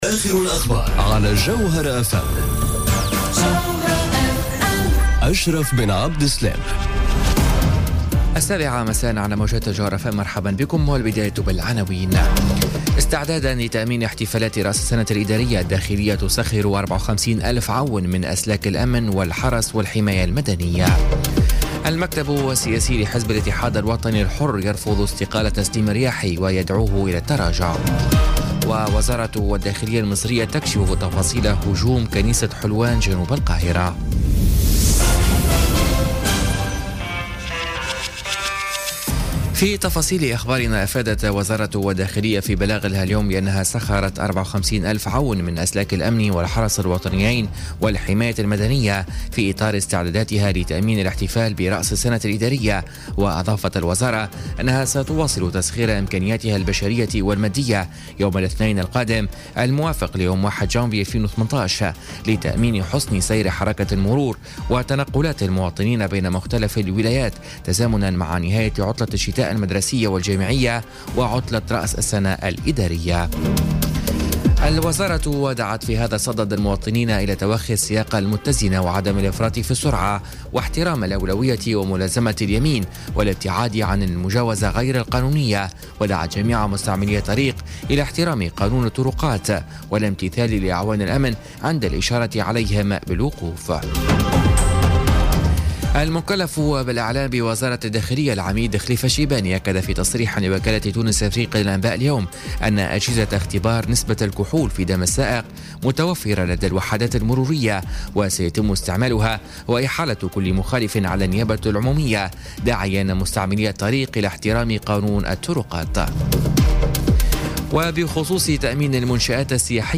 نشرة أخبار السابعة مساءً ليوم الجمعة 29 ديسمبر 2017